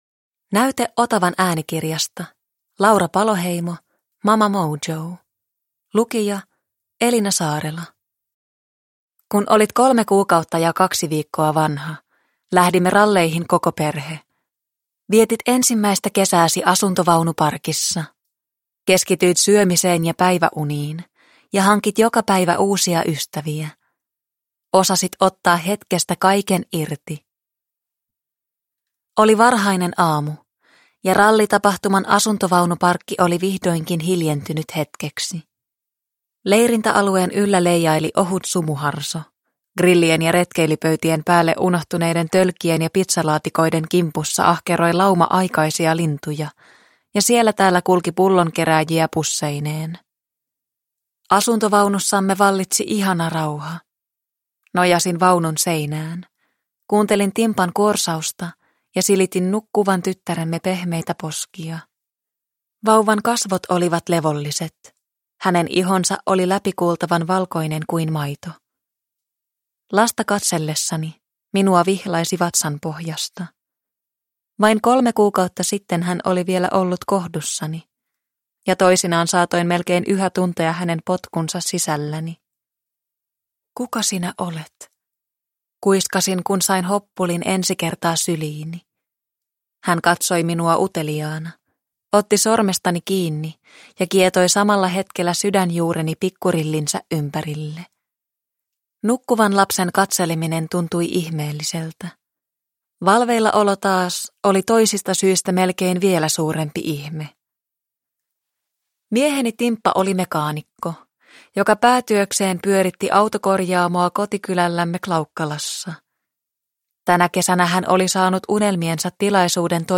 Mama Mojo – Ljudbok – Laddas ner